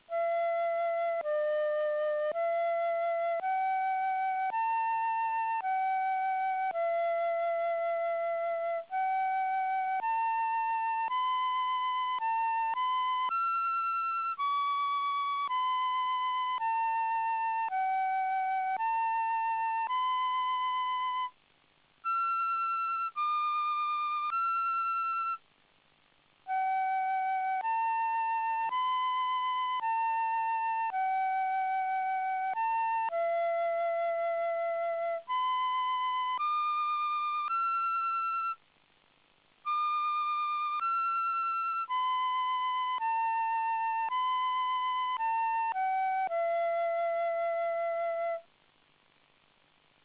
KIMIGAYO, HIMNO NACIONAL
(392 K), versión flauta.